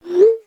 woop.ogg